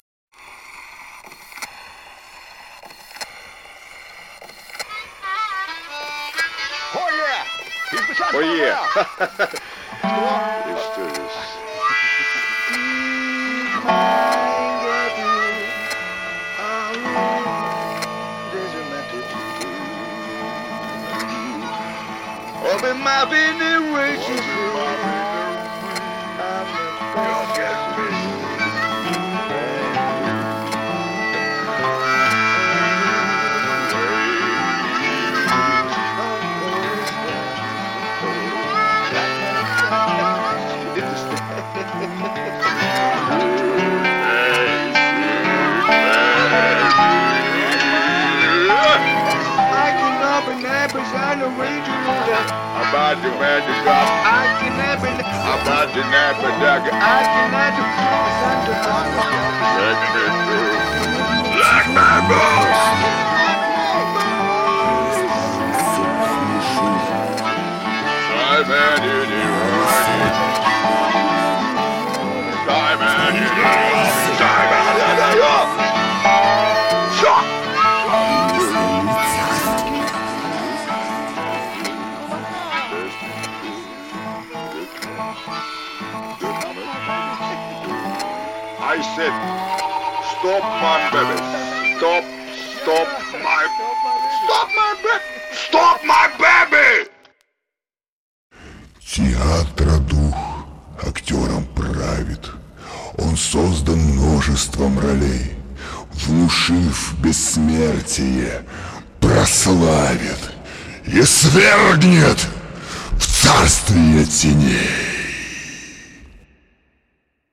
Жанр: Жанры / Рок